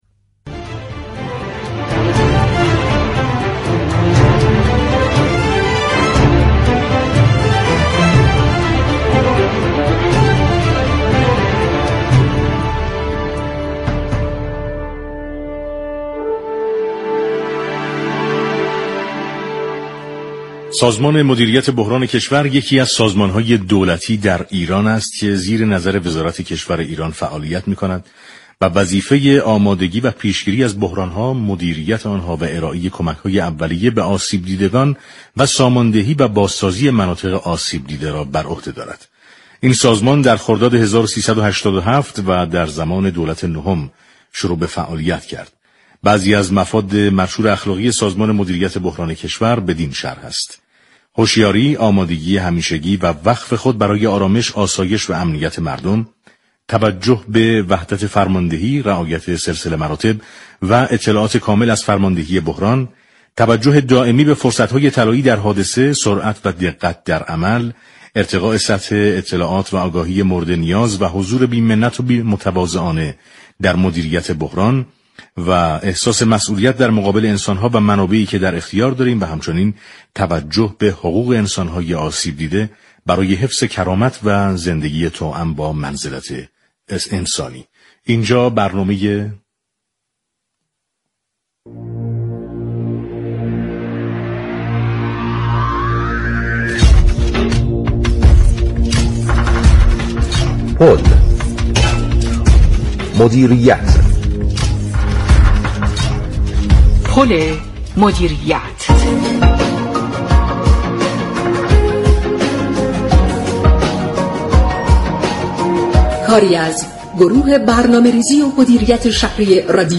به گزارش پایگاه اطلاع رسانی رادیو تهران، علی نصیری رئیس سازمان پیشگیری و مدیریت بحران شهر تهران در گفت و گو با برنامه «پل مدیریت» با بیان اینكه سازمان‌هایی از قبیل سازمان پیشگیری و مدیریت بحران تهران و كشور و تمامی بخش‌هایی كه با مدیریت بحران در ارتباط هستند؛ مسئول بحران نیستند گفت: بلكه تمامی دستگاه‌ها و ادارات به سهم خود مسئول بحران هستند.